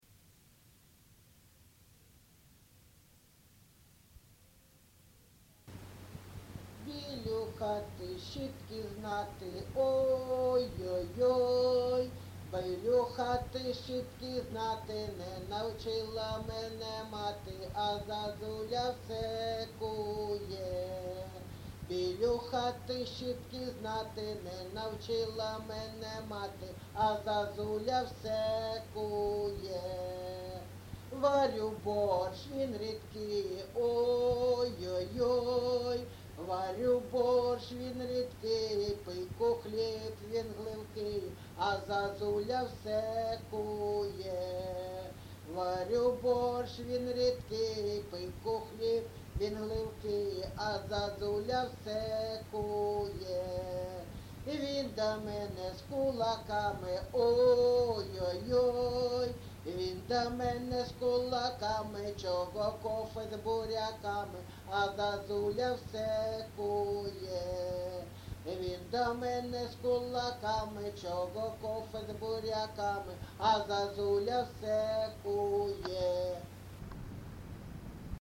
ЖанрЖартівливі
Місце записус. Григорівка, Артемівський (Бахмутський) район, Донецька обл., Україна, Слобожанщина